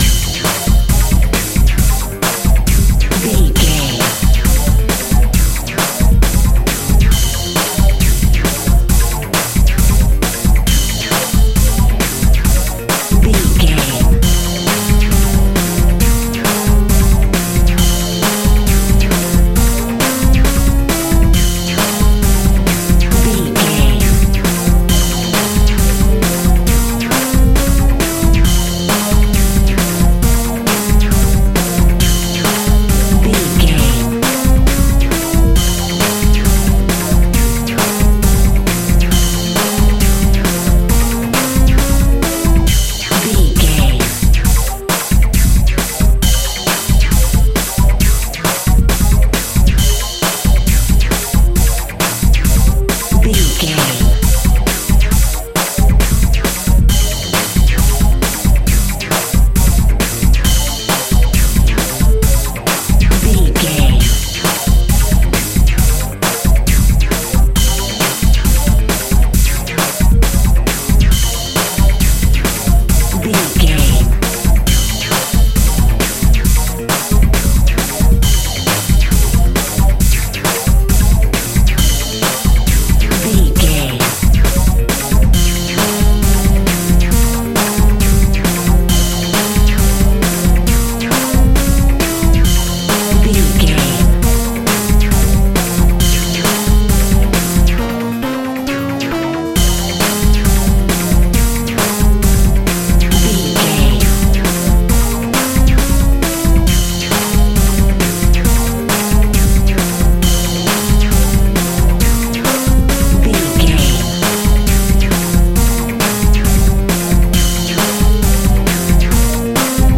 Explosive Pop.
Fast paced
Aeolian/Minor
aggressive
dark
driving
energetic
synthesiser
drum machine
breakbeat
synth lead
synth bass